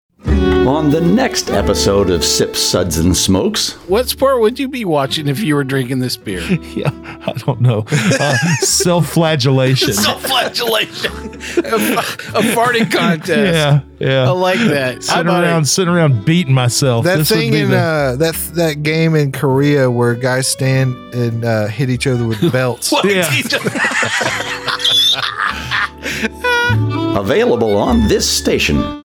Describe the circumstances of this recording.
Recording Location: Nashville, TN